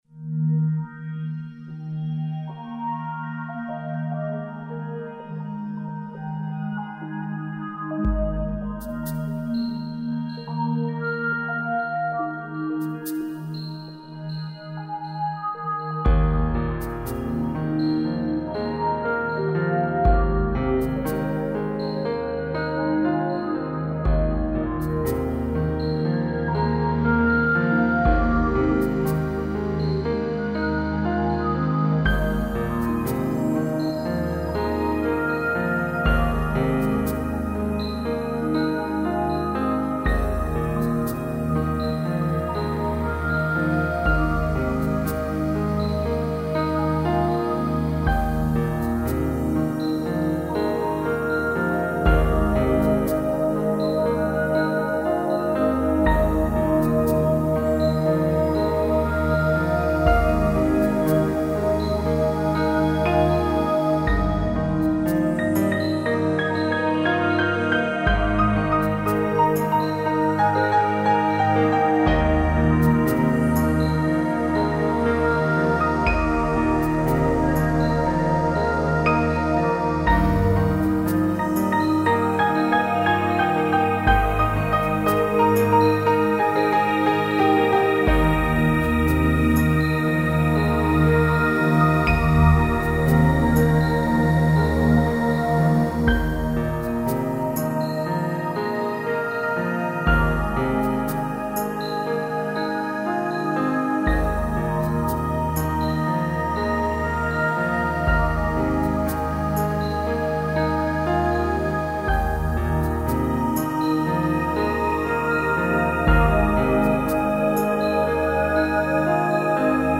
ピアノ曲一覧
α派溢れる正統派癒し系。